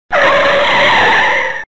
دانلود آهنگ ترمز ماشین 1 از افکت صوتی حمل و نقل
دانلود صدای ترمز ماشین 1 از ساعد نیوز با لینک مستقیم و کیفیت بالا
جلوه های صوتی